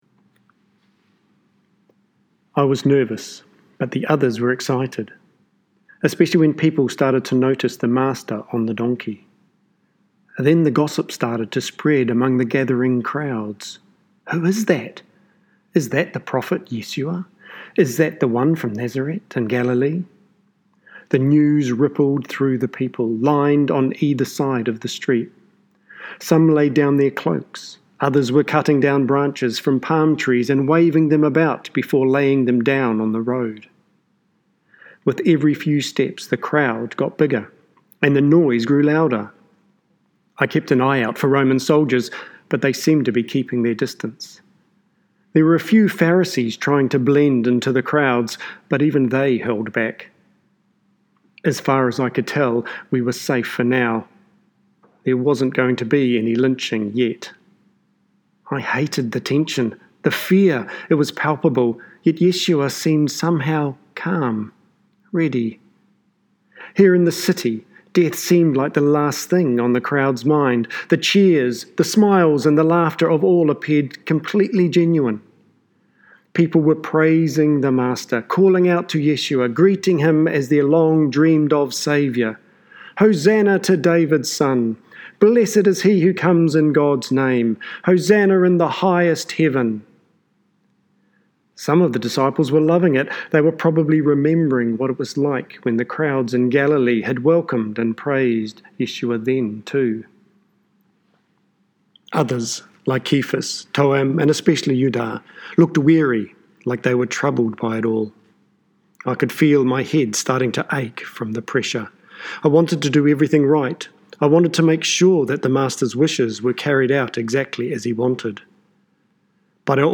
Sermons | All Saints Parish Palmerston North
Guest Speaker